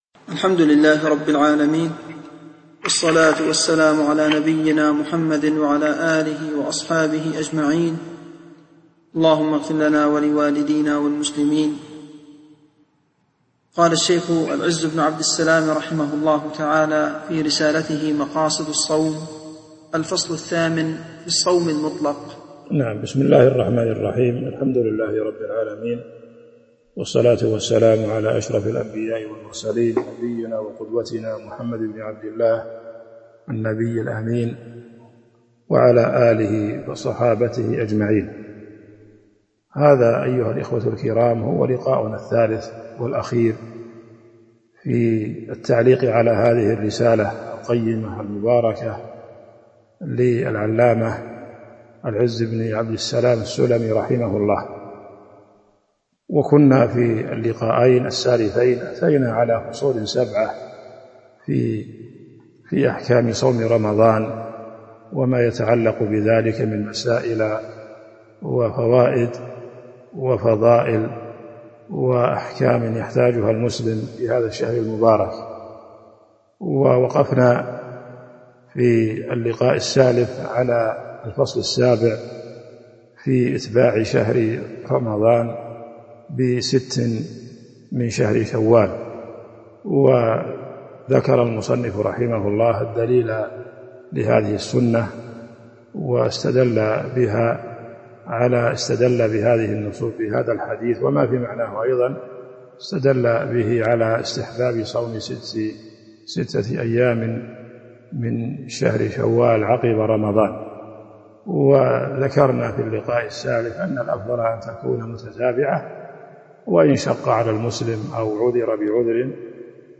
تاريخ النشر ١٤ رمضان ١٤٤٢ هـ المكان: المسجد النبوي الشيخ